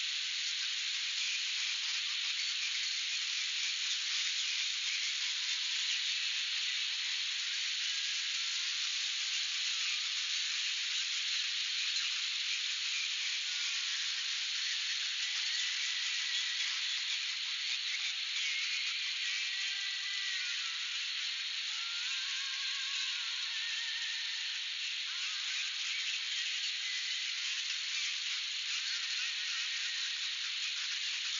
GuardRadio.ogg